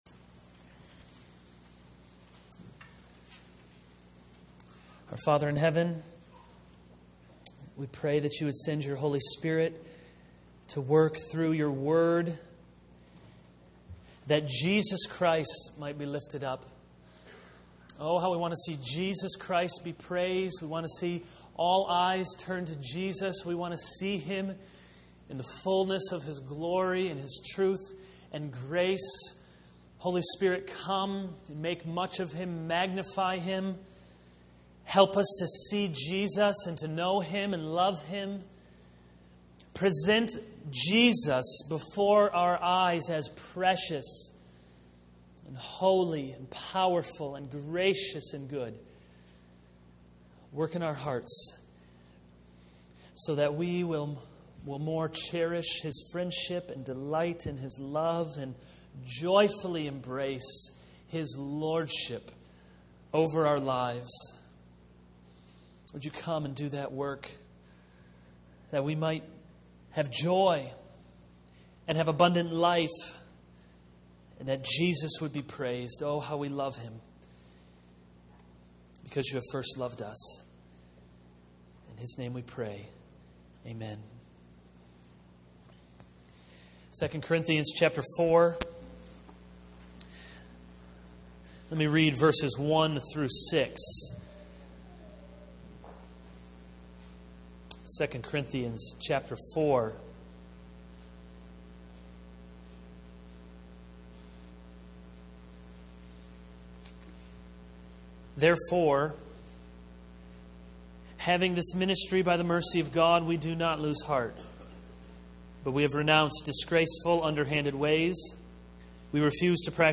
This is a sermon on 2 Corinthians 4:1-6.